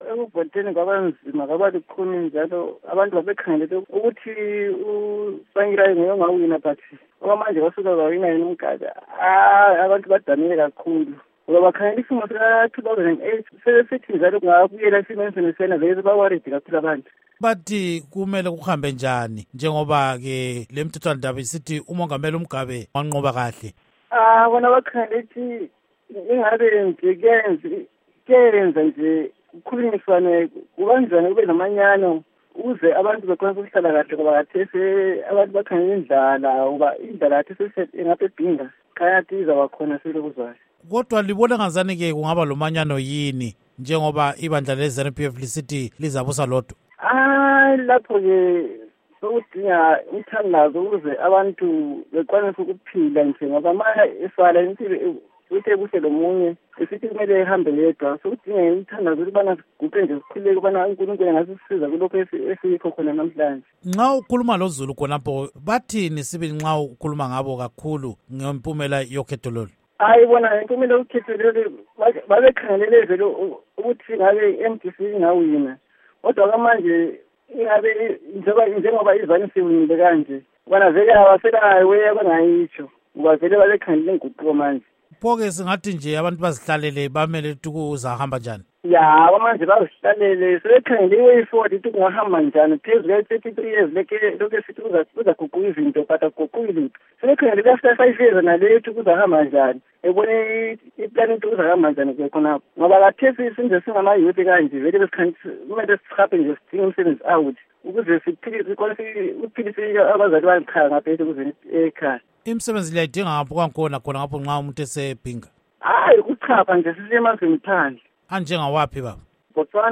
Ingxoxo Esiyenze